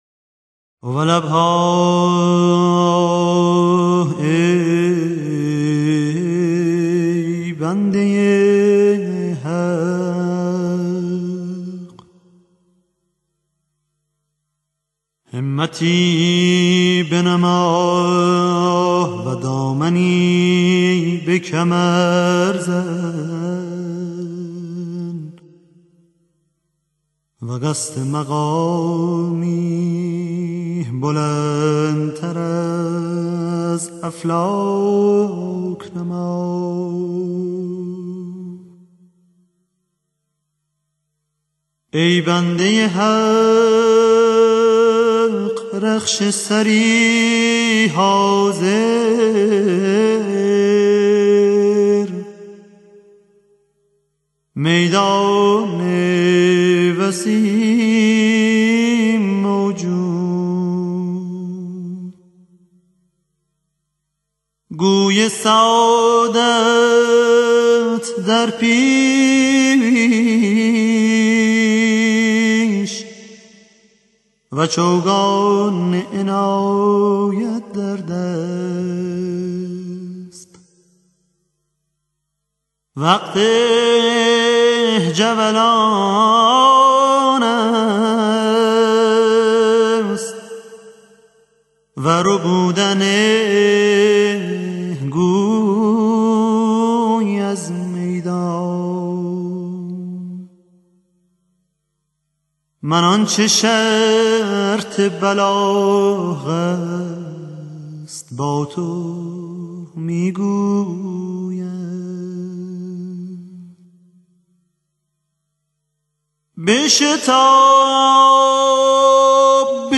با تحیت لوحی زیبا با لحنی بسیار زیبا موید باشید